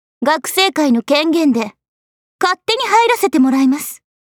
cha13kasori_voice_sample.mp3